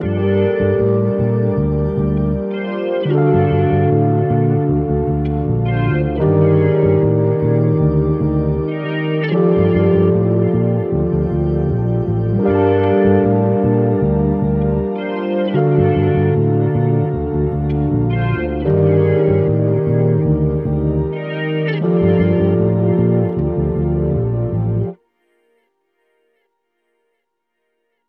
Loop pack for dark melodies like Dark R&B, Trap, Dark Pop and more.
Classic synth tones that add that special and organic textures to your songs.
• High-Quality RnB Samples 💯